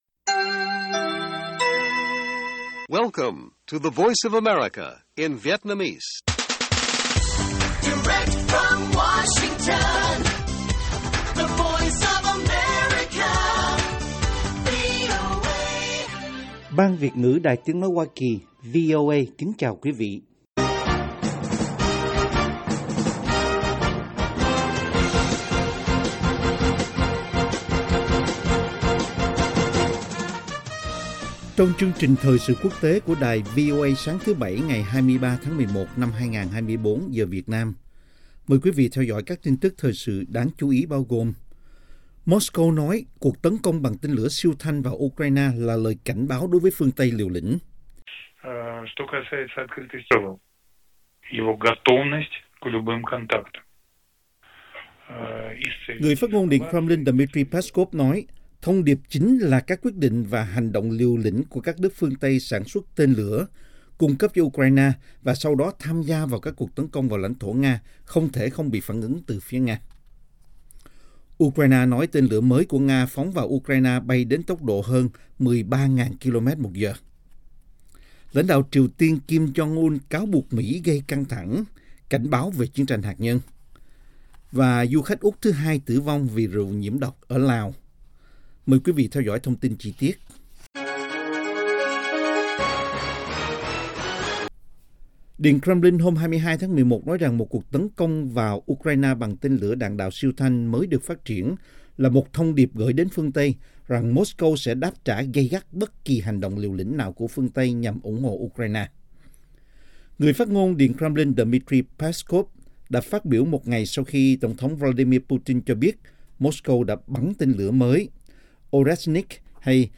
Tin tức mới nhất và các chuyên mục đặc biệt về Việt Nam và Thế giới. Các bài phỏng vấn, tường trình của các phóng viên ban Việt ngữ về các vấn đề liên quan đến Việt Nam và quốc tế.